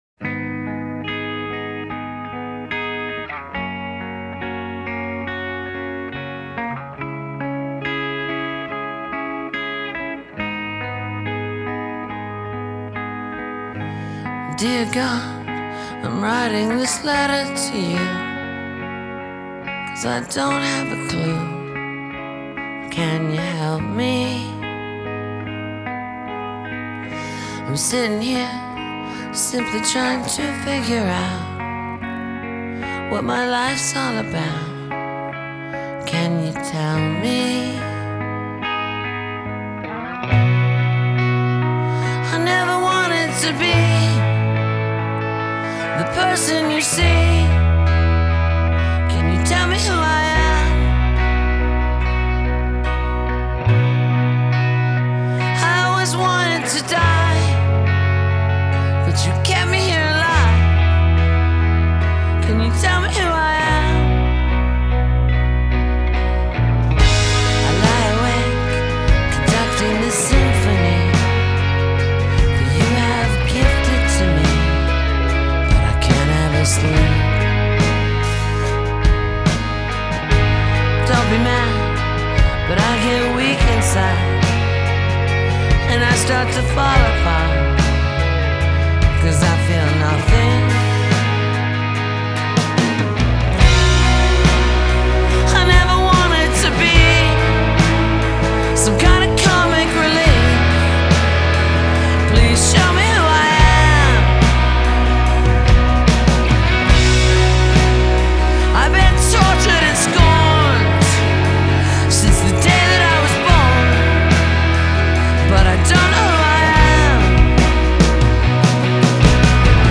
Жанр: Гранж - Рок